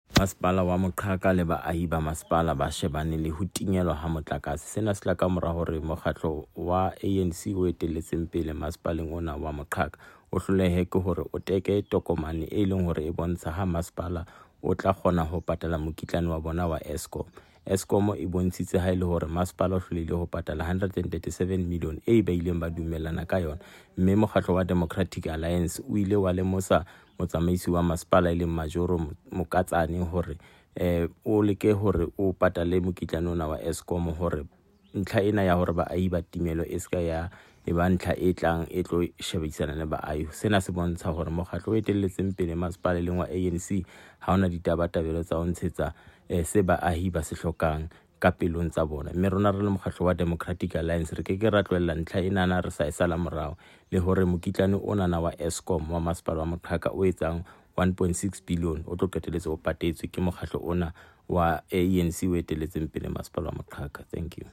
Sesotho soundbites by Cllr David Nzunga.